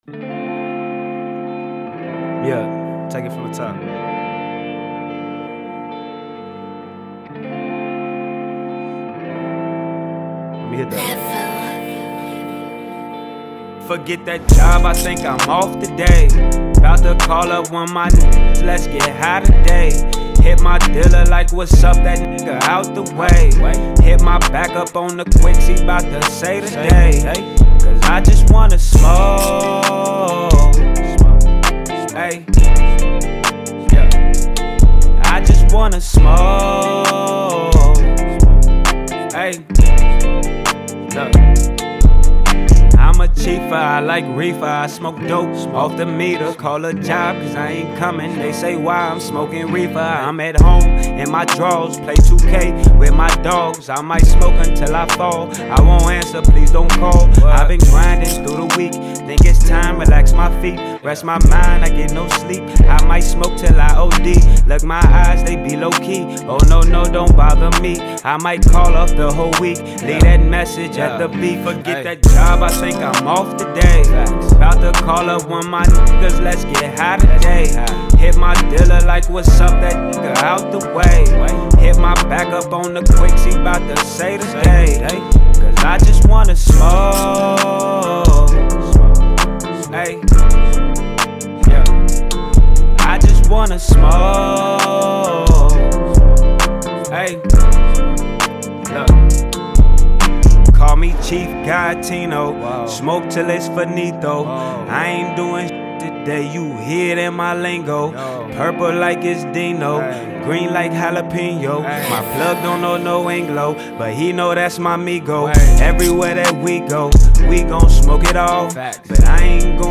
Rap
refreshing and upbeat track